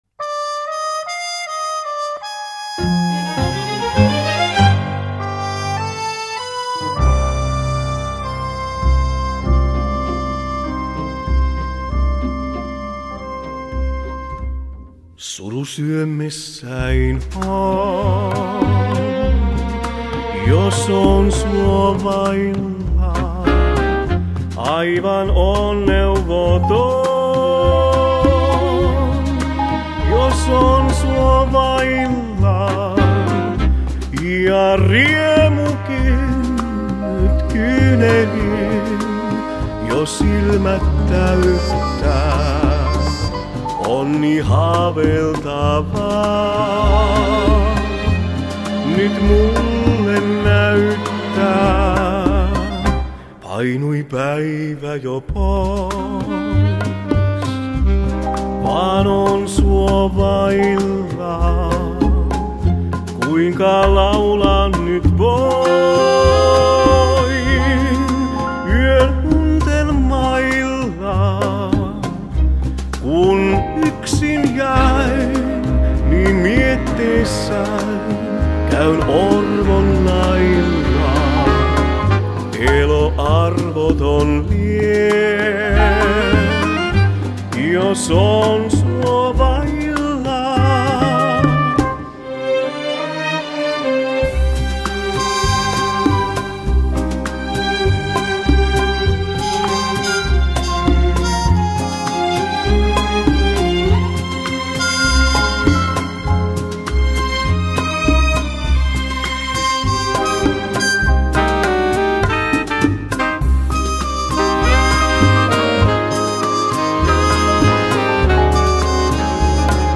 Pehmeä-ääninen